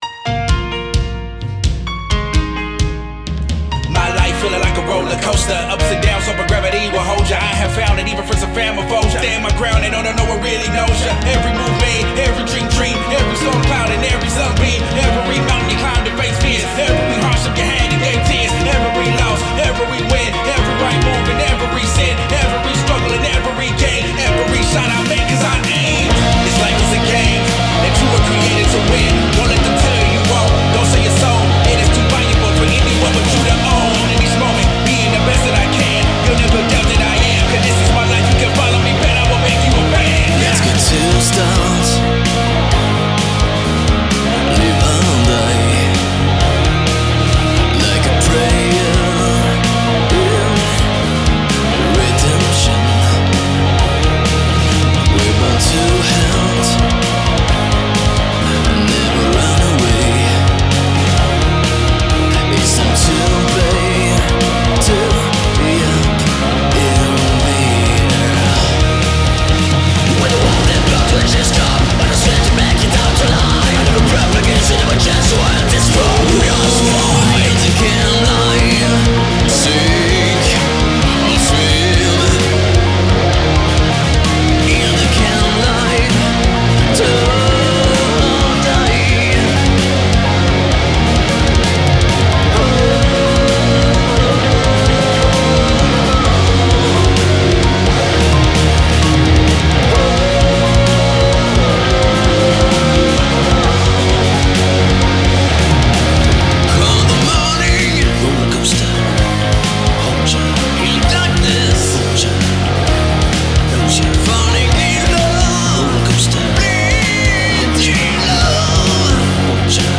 Mixé